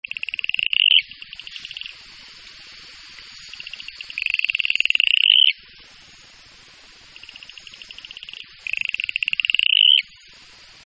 chant: chante au sol à proximité des criques, audible à une dizaine de mètres, exclusivement diurne:
chant atelopus.mp3